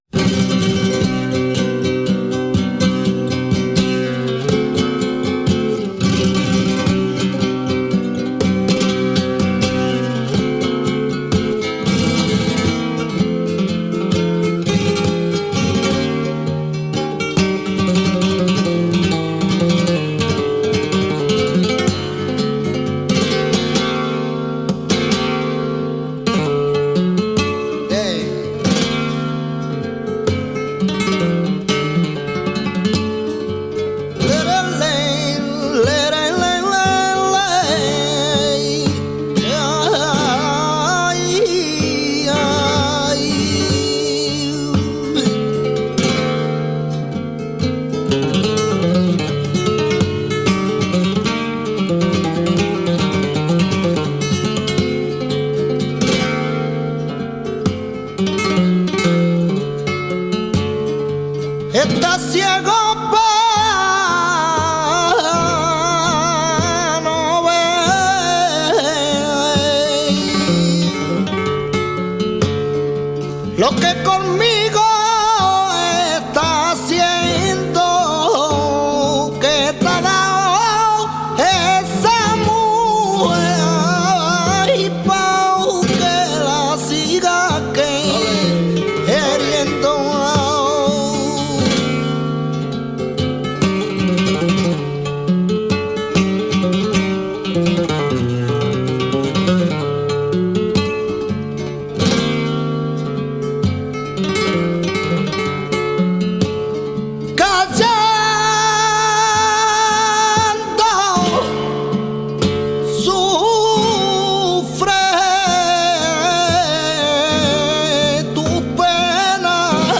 Bulerías por soleá